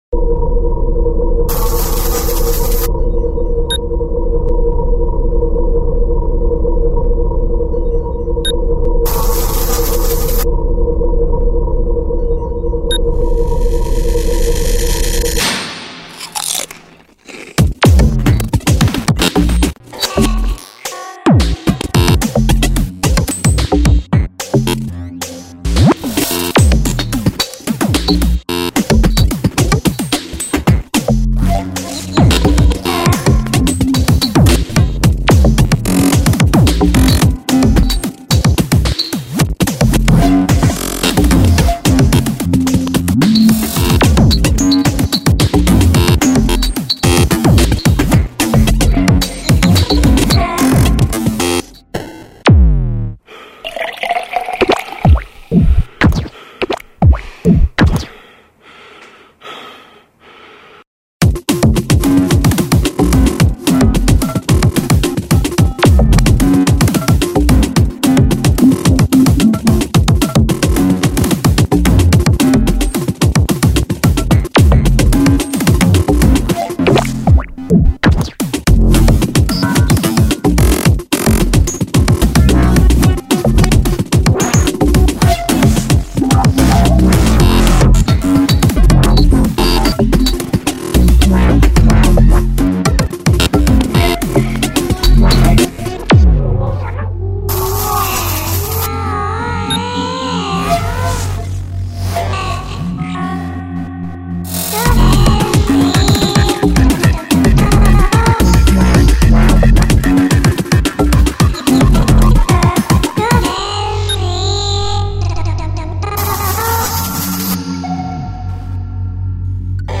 Эксперементальный трэк.